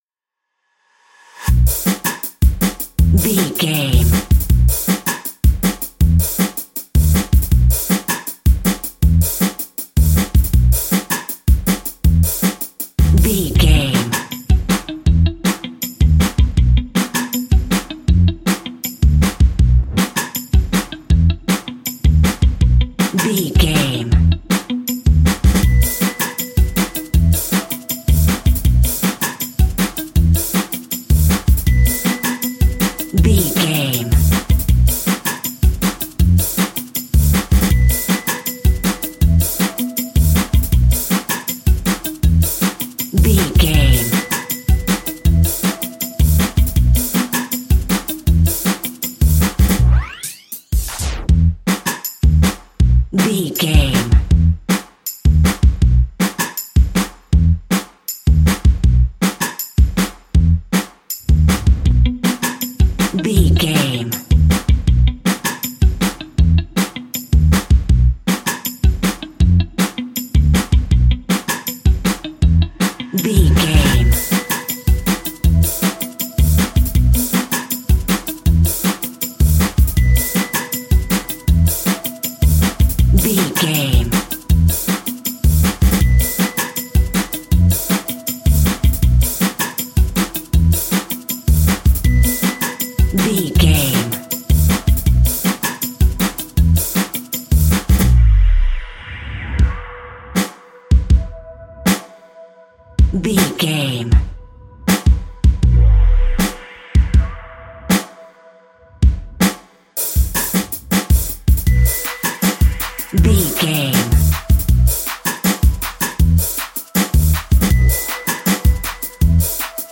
Ionian/Major
Fast
pop rock
indie pop
fun
energetic
uplifting
guitars
bass
drums
piano
organ